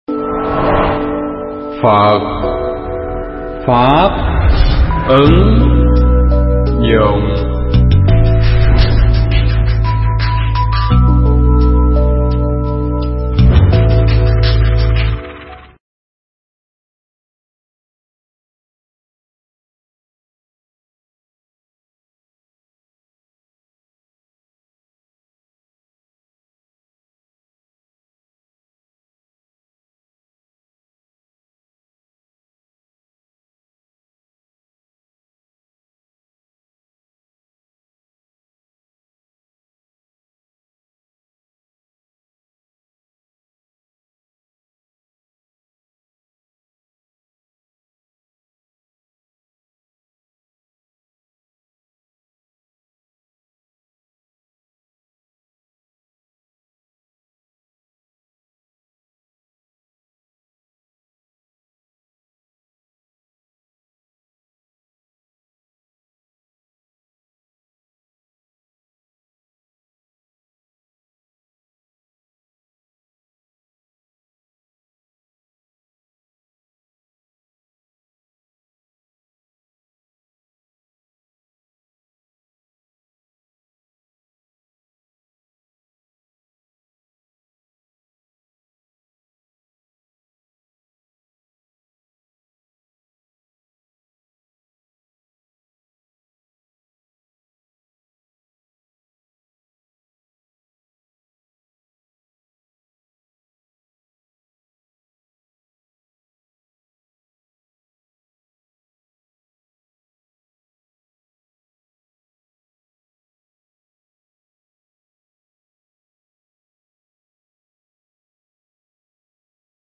Pháp thoại Tứ Diệu Đế
thuyết giảng tại chùa Ấn Quang (TPHCM)